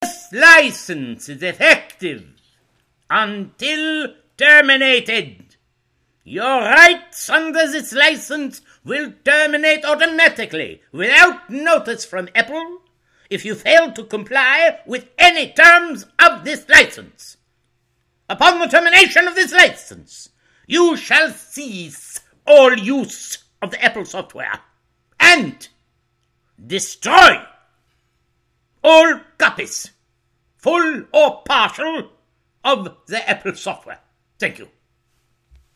Richard Dreyfuss liest Apple EULA
Auf seine eigene Art und Weise. Sehr schön finde ich den Hitler-Duktus in diesem Beispiel.